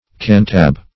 Search Result for " cantab" : The Collaborative International Dictionary of English v.0.48: Cantab \Can"tab\, n. [Abbreviated from Cantabrigian.]